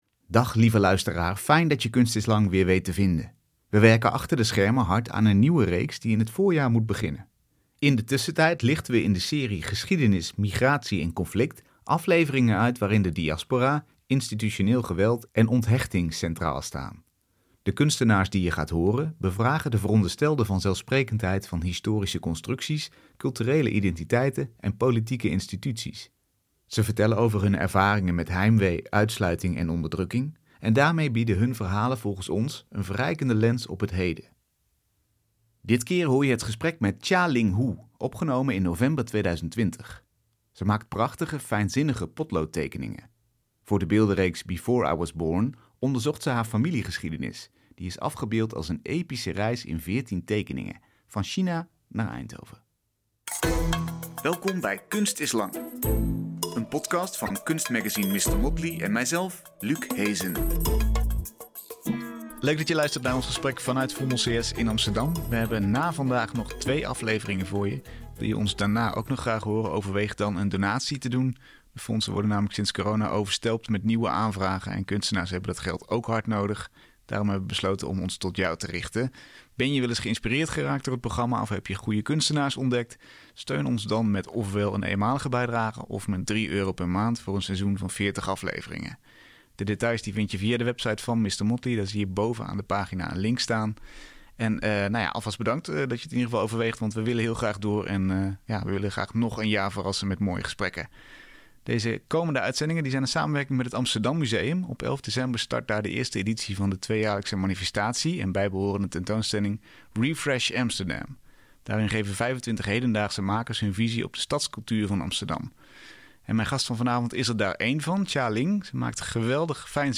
In de tussentijd lichten we in de serie Geschiedenis, migratie en conflict afleveringen uit waarin de diaspora, institutioneel geweld en onthechting centraal staan. De kunstenaars die je gaat horen, bevragen de veronderstelde vanzelfsprekendheid van historische constructies, culturele identiteiten en politieke instituties, en ze vertellen over hun ervaringen met heimwee, uitsluiting en onderdrukking.